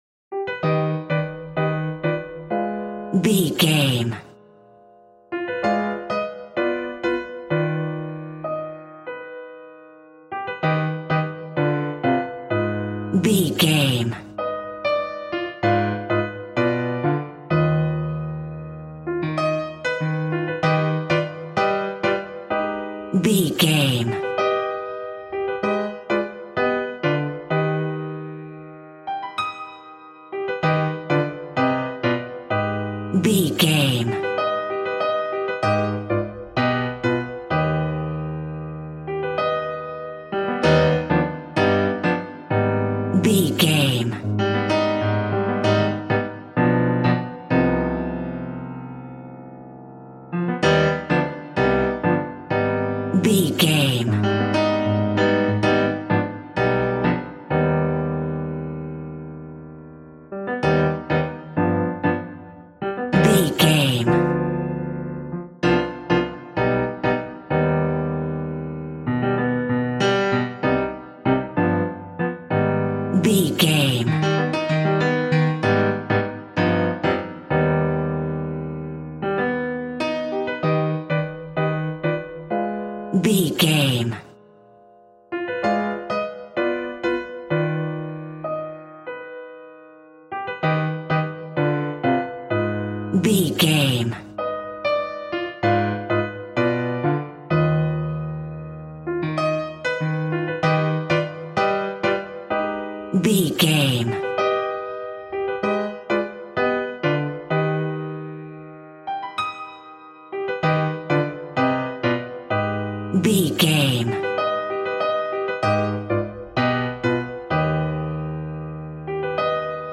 Aeolian/Minor
scary
ominous
dark
haunting
eerie
horror
creepy
Acoustic Piano